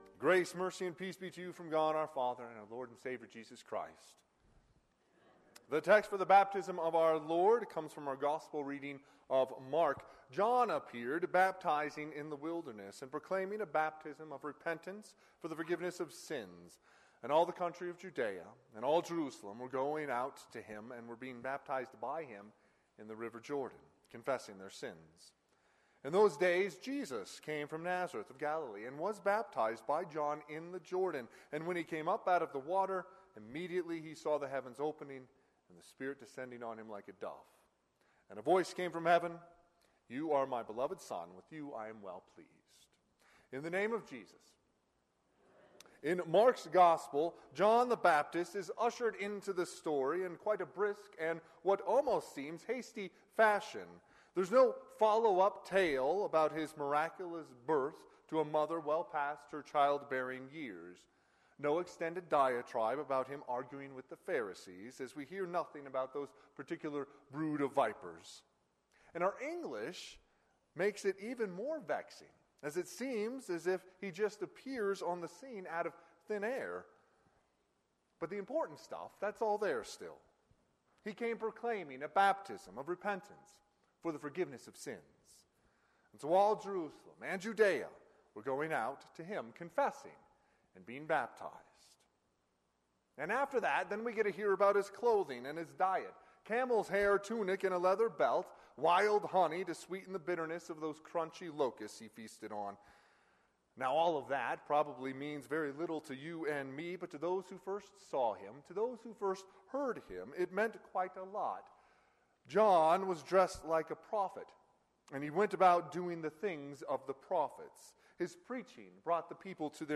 Sermon - 01/14/2024 - Wheat Ridge Lutheran Church, Wheat Ridge, Colorado